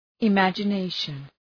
Shkrimi fonetik{ı,mædʒə’neıʃən}
imagination.mp3